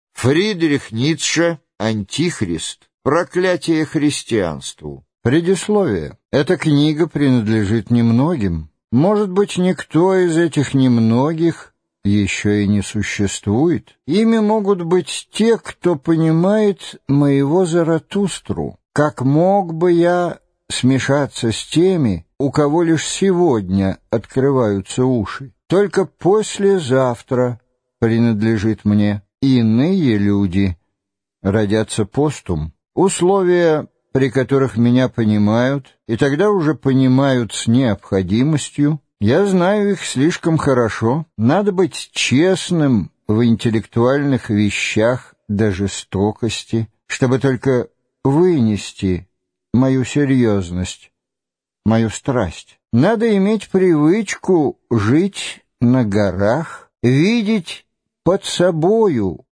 Аудиокнига Антихрист, ЕССЕ НОМО | Библиотека аудиокниг